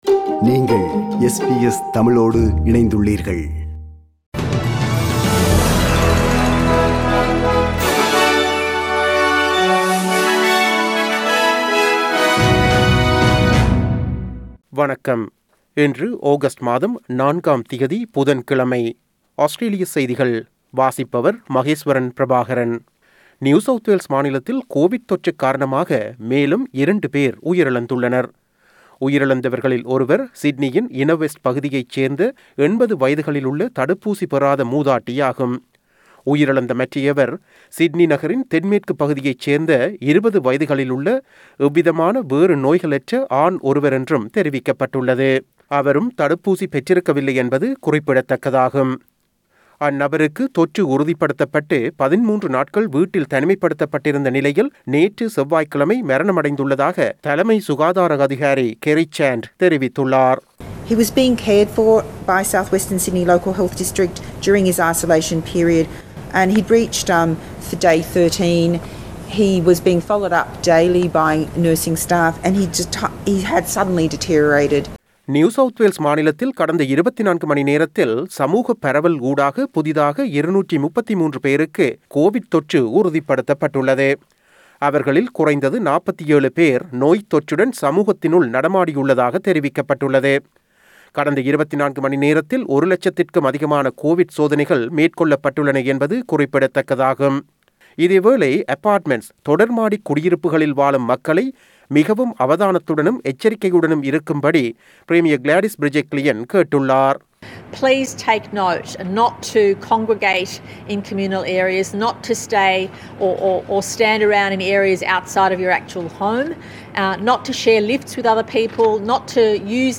Australian news bulletin for Wednesday 04 August 2021.